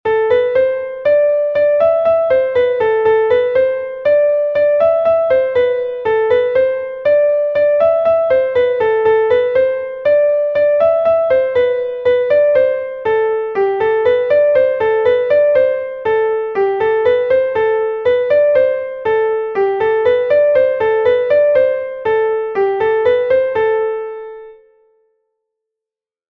Ridée Pevar Den I est un Laridé de Bretagne enregistré 1 fois par Pevar Den